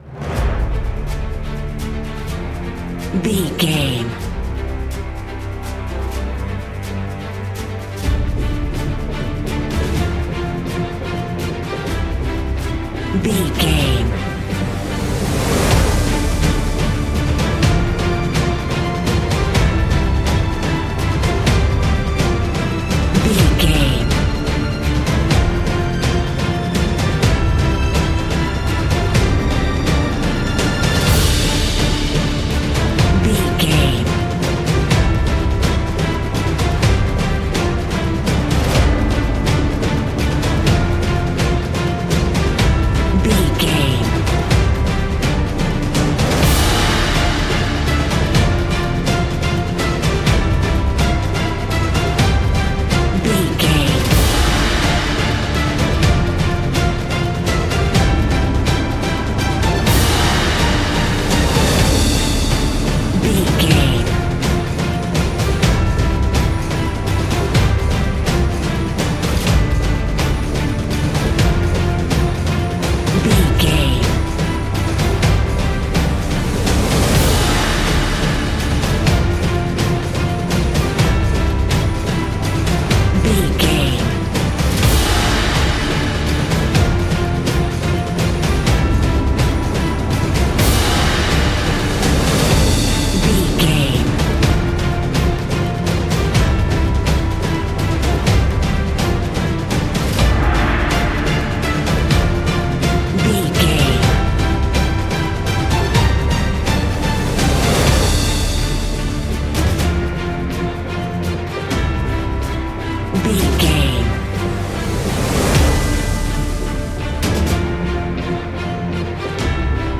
Fast paced
Aeolian/Minor
aggressive
cinematic
driving
frantic
brass
cello
double bass
drums
horns
piano
strings
synthesizers
violin